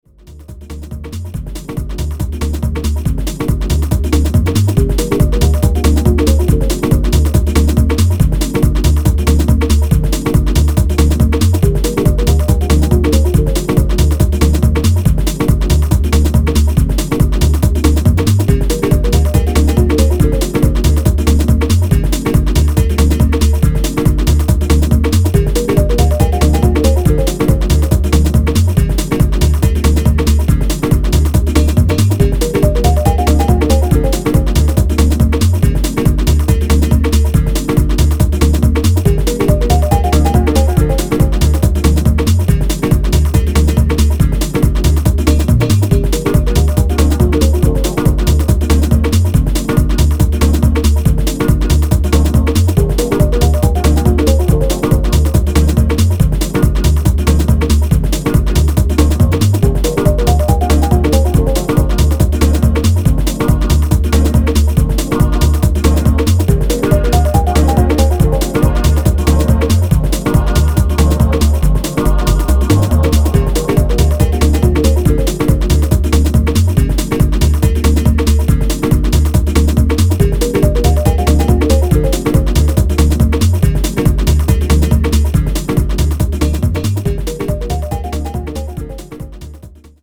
チルいパッドが心地良い